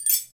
Index of /90_sSampleCDs/Roland L-CD701/PRC_Asian 2/PRC_Windchimes
PRC CHIME04L.wav